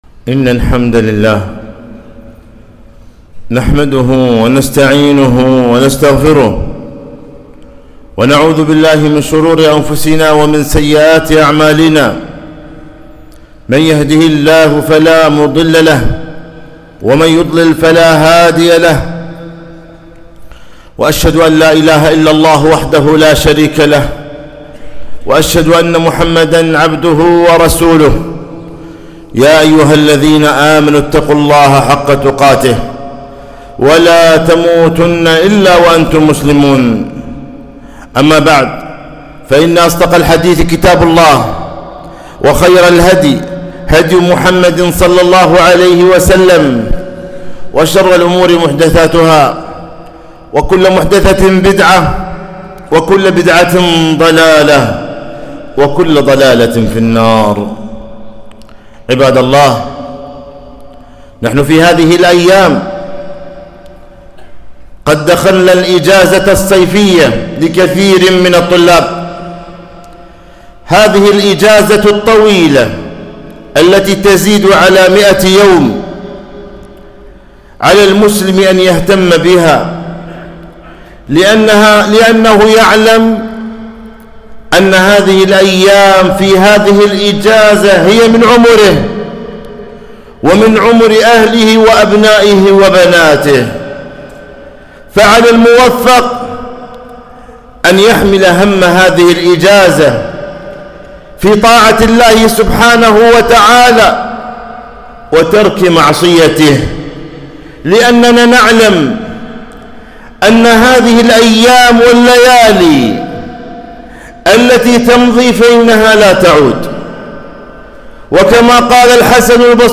خطبة - اغتنام الإجازة الصيفية